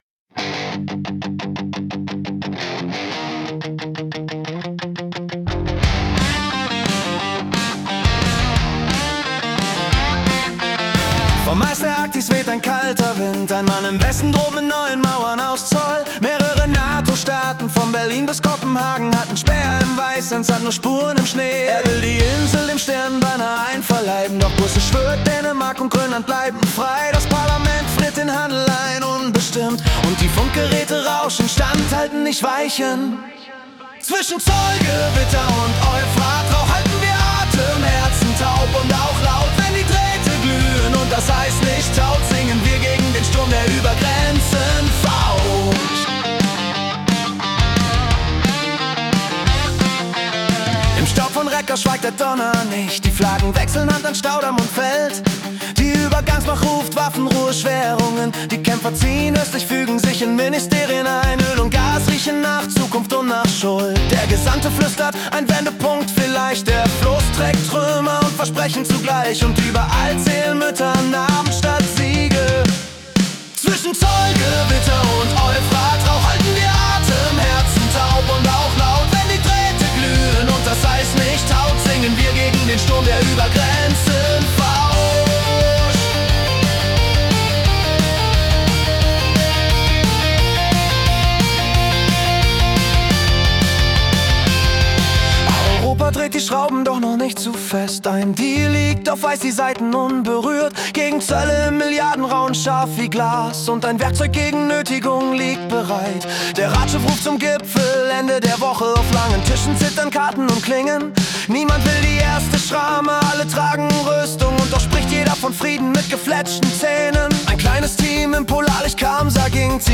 Die Nachrichten vom 19. Januar 2026 als Rock-Song interpretiert.
Erlebe die Geschichten der Welt mit fetzigen Riffs und kraftvollen Texten, die Journalismus...